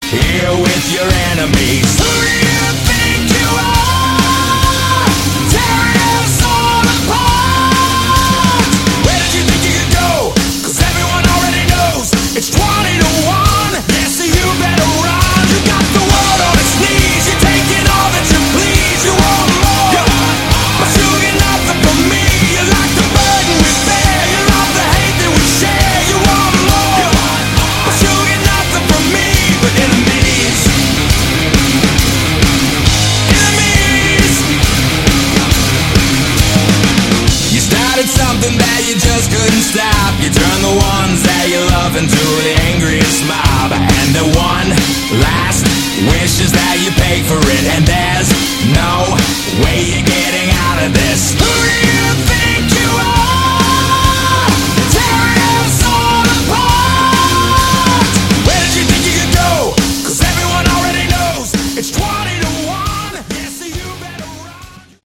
Category: Modern hard Rock
vocals
drums
guitar